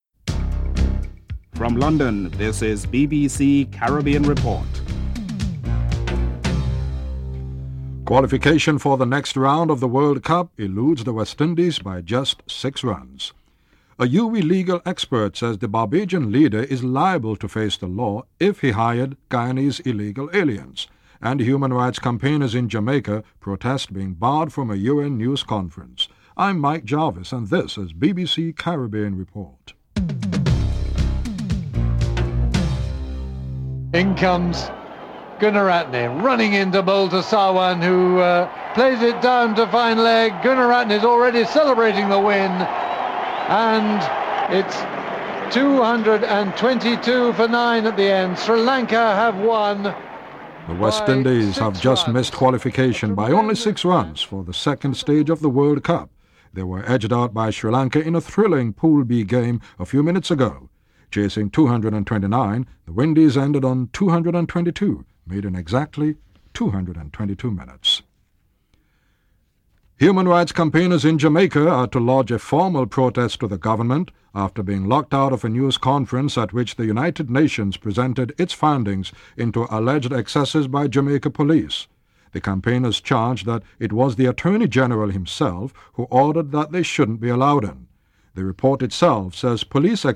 Diane Bunyan, Leader of the Bristol City Council says there is a lack of funding.